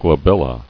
[gla·bel·la]